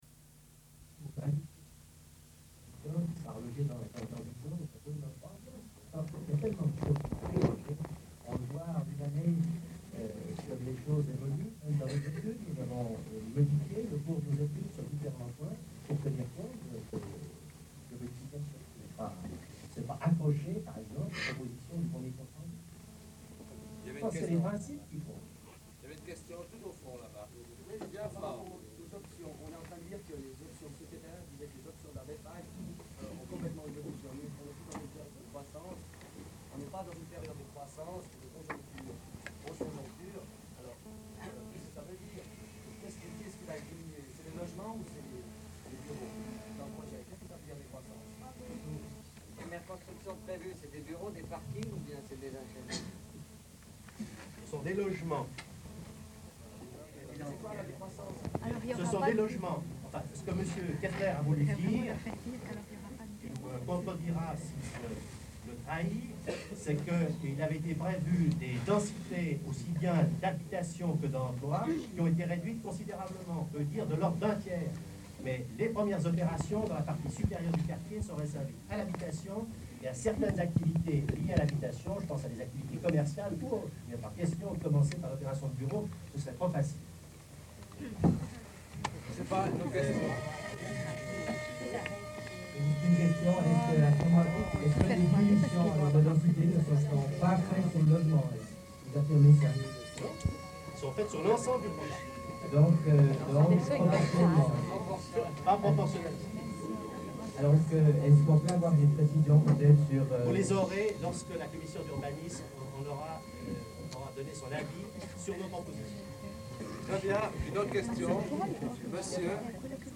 Enregistrement de la séance publique du 11 mars 1975 - Cassette 2 - face A - Archives contestataires
Il s'agit de l'enregistrement d'une soirée publique organisée par le Centre de loisirs des Asters le 11 mars 1975 dans les locaux du Centre.
01'40'' Bruit parasite